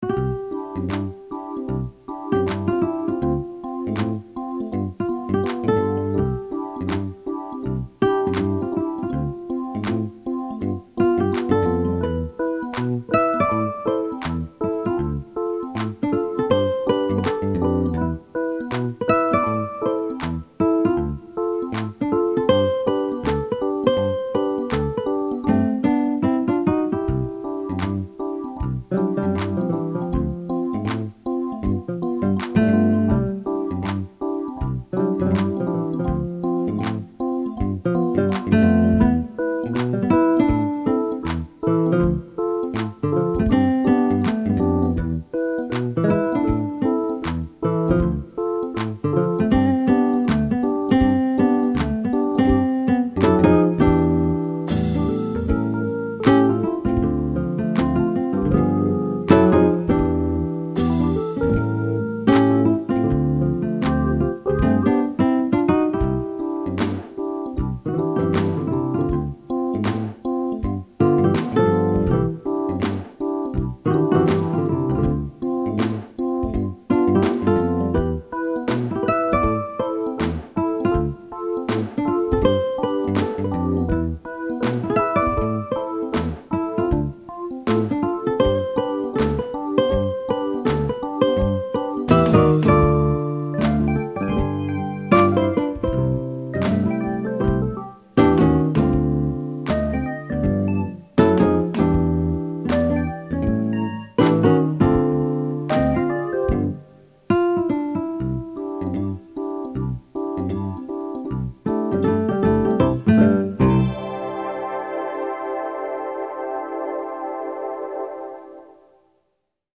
Jolly, just a bit of fluff to clap to, nothing more.